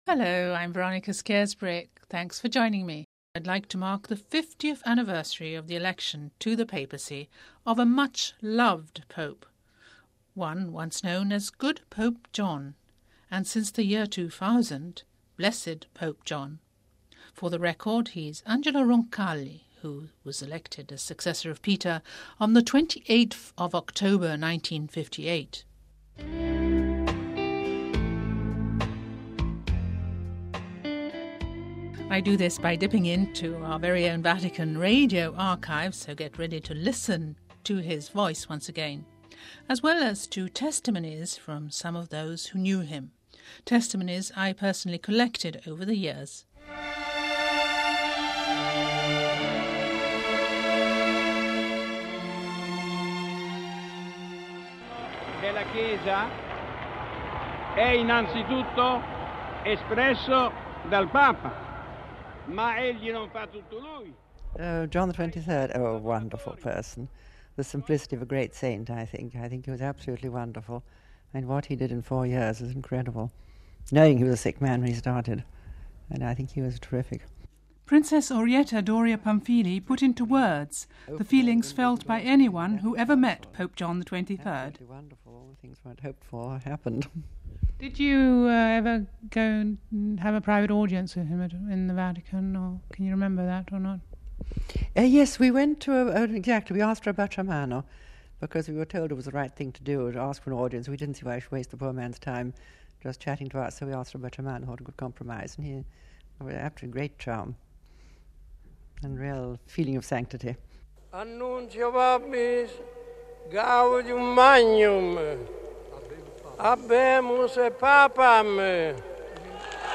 Blessed John XXIII, the much loved pope who launched the Catholic Church into one of its most momentous epochs was elected as successor of Peter exactly 50 years ago. Listen to exclusive sound from our Vatican Radio archives...